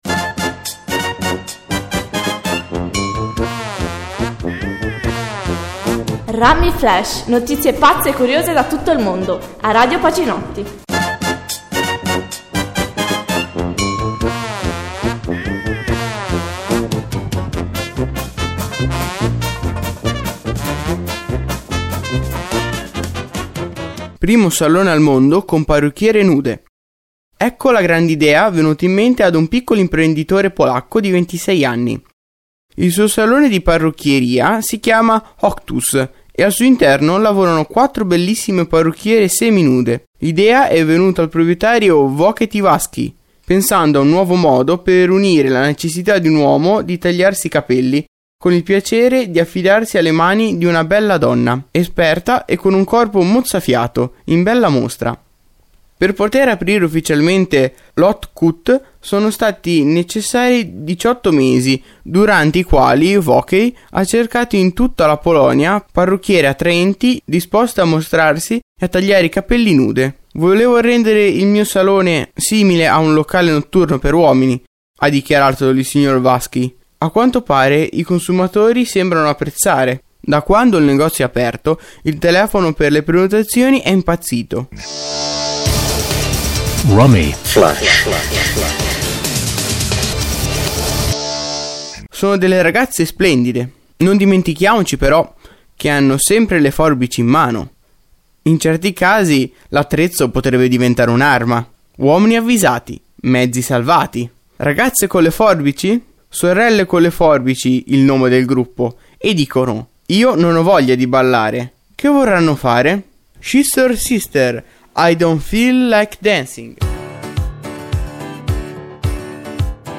Radioweb Pacinotti Notizie curiose e brano di musica con relazione alla notizia